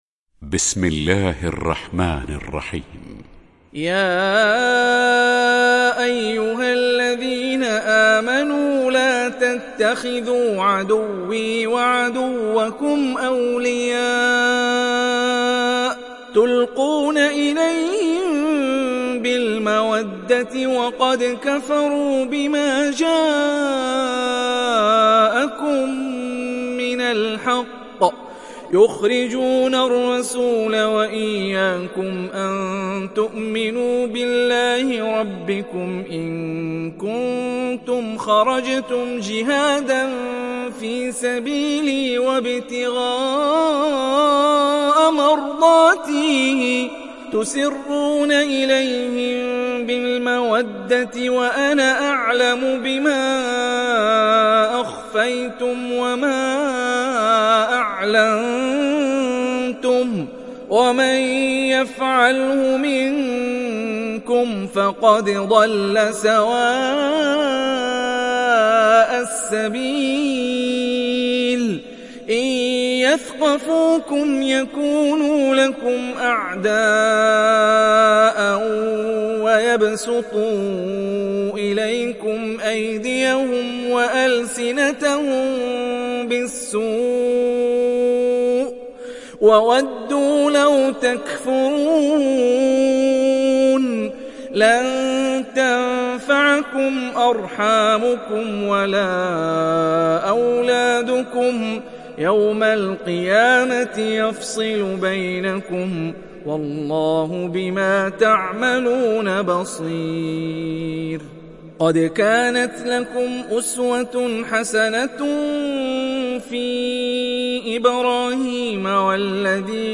Surat Al Mumtahinah mp3 Download Hani Rifai (Riwayat Hafs)